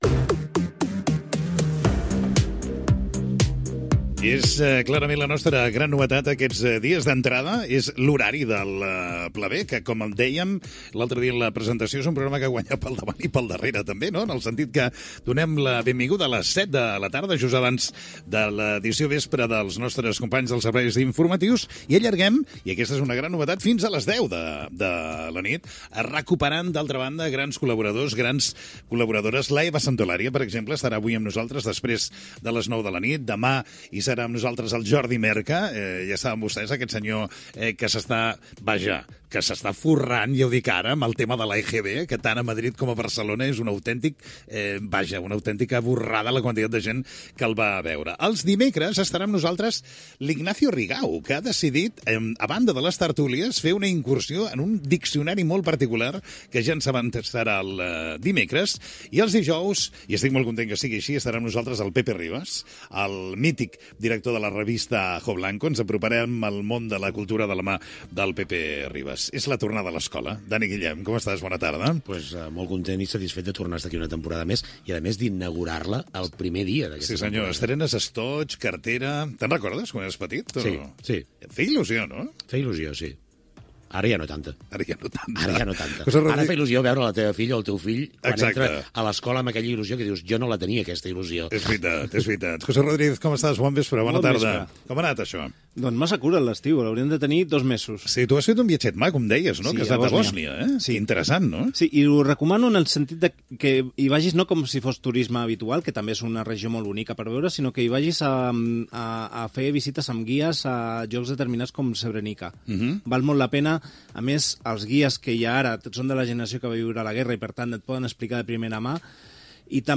Inici del primer programa de la temporada 2025-2026.
Entreteniment
FM